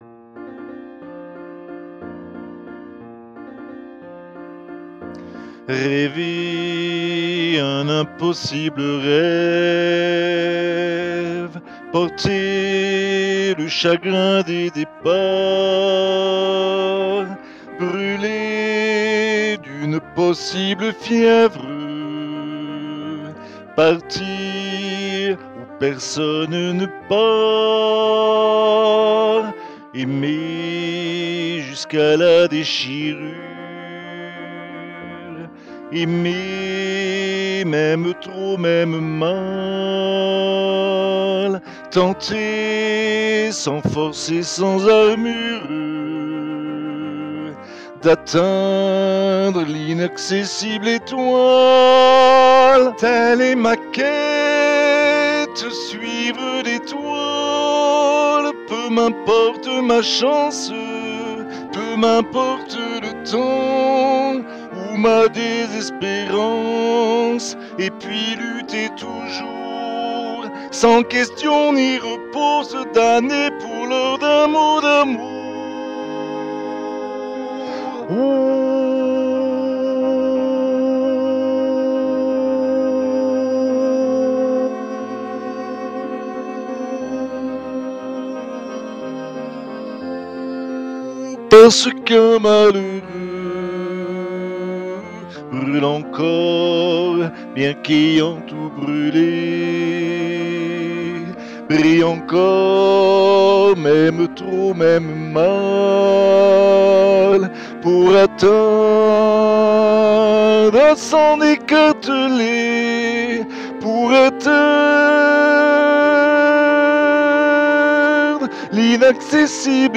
voix chantée